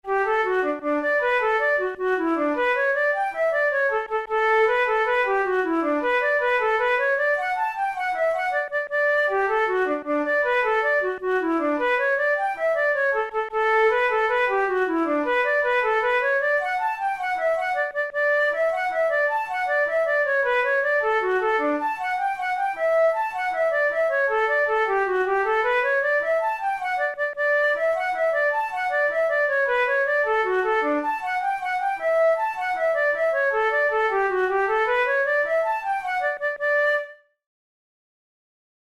InstrumentationFlute solo
KeyD major
Time signature6/8
Tempo104 BPM
Jigs, Traditional/Folk
Traditional Irish jig